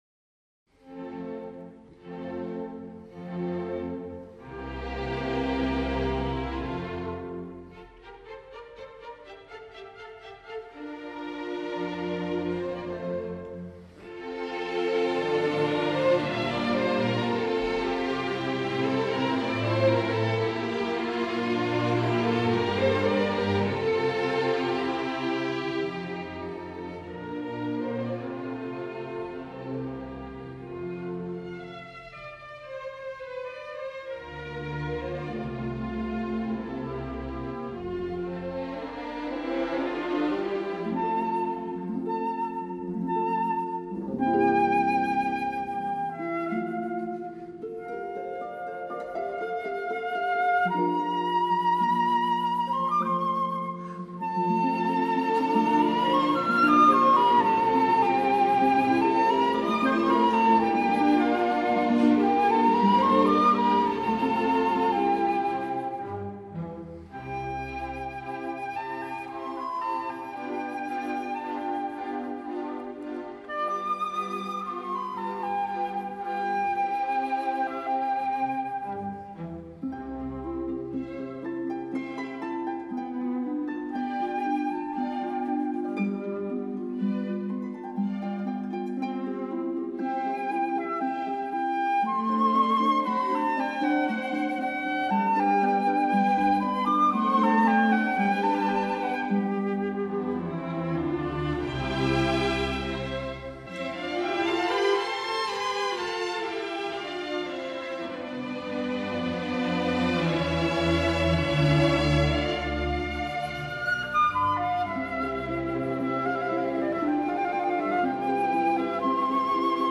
08. Mozart - Flut & Harp Concerto in C.mp3